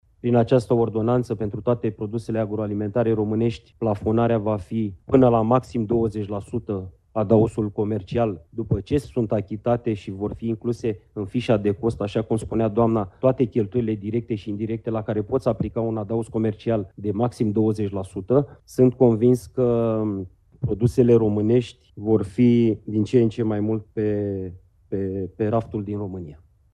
Ministrul Agriculturii, Florin Barbu: „Sunt convins că produsele românești vor fi din ce în ce mai mult pe rafturile din România”